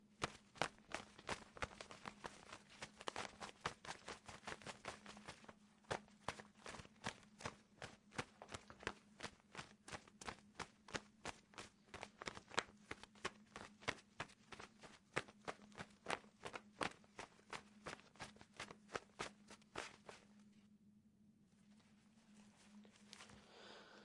手套布拍打奔跑
描述：手套以奔腾的节奏拍打着
Tag: 舞动 拍手 耳光 手套